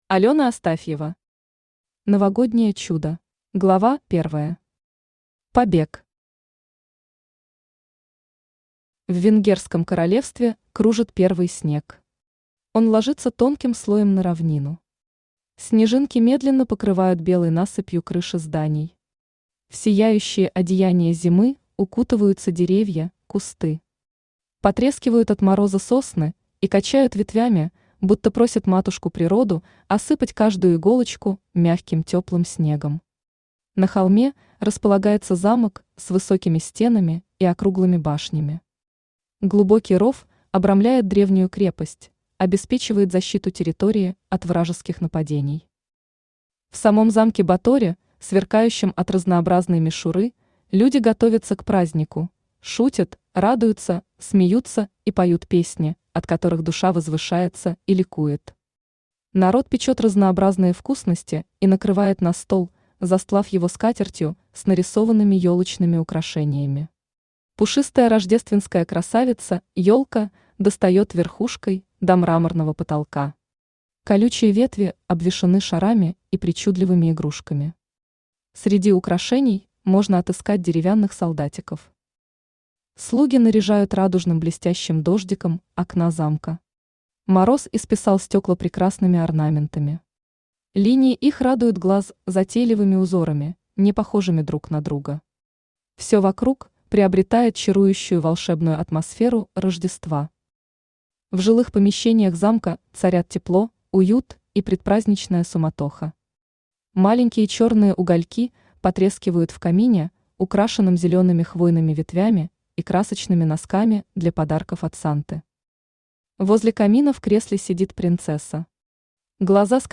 Aудиокнига Новогоднее чудо Автор Алёна Астафьева Читает аудиокнигу Авточтец ЛитРес.